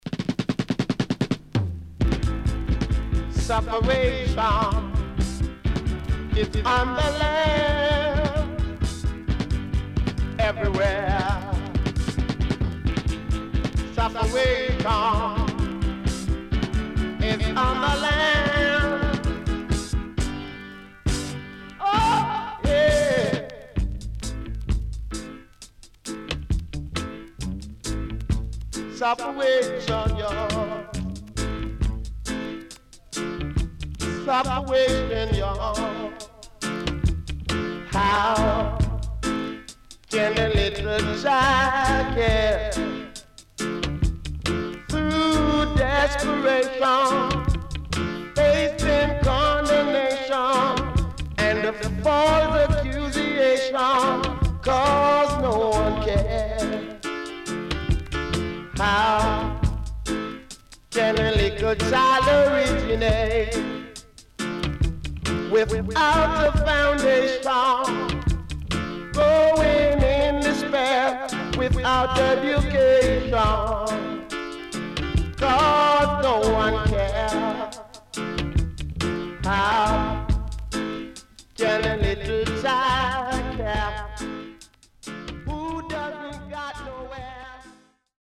SIDE A:所々チリノイズ、プチノイズ入ります。